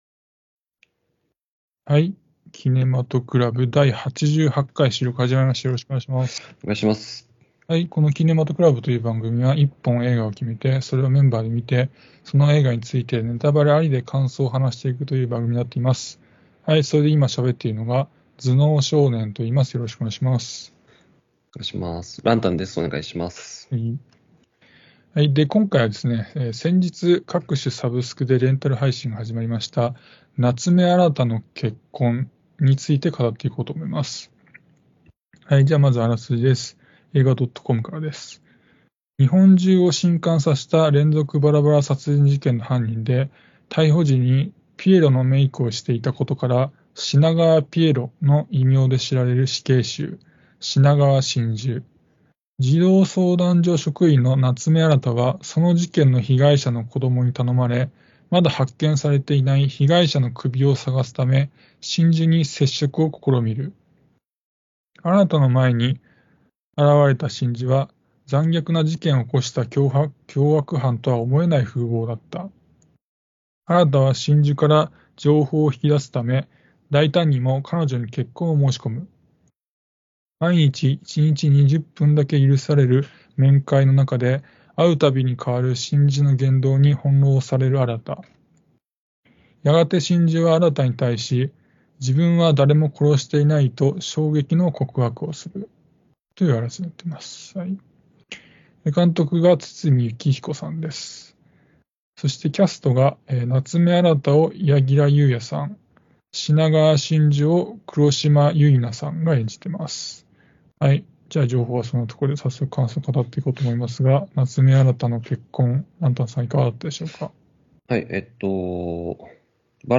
映画好きの男達が毎回映画の課題作を決め、それを鑑賞後感想を話し合います。